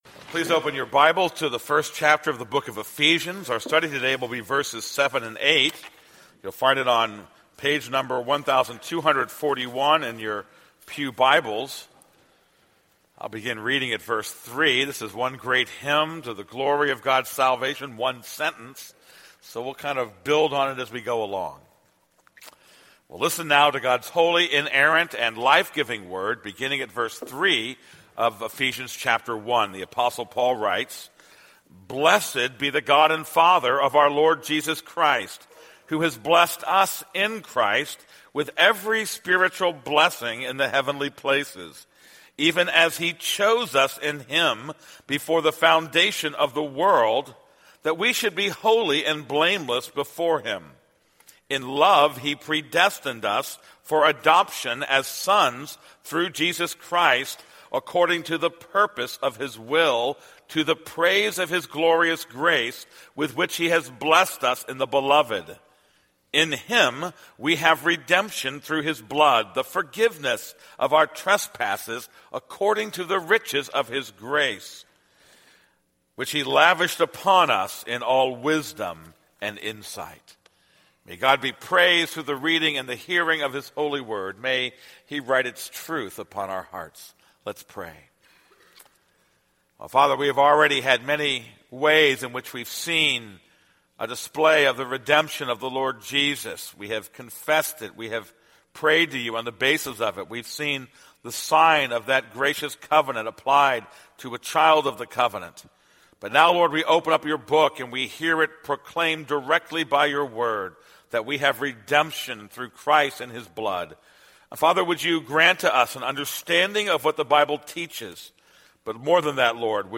This is a sermon on Ephesians 1:7-8.